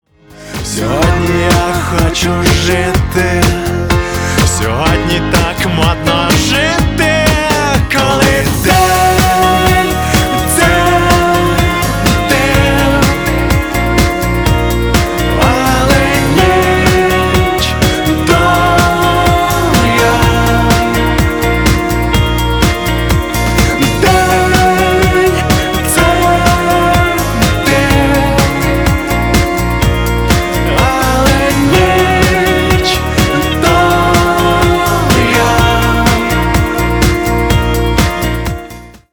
• Качество: 320, Stereo
мужской вокал
украинский рок
поп-рок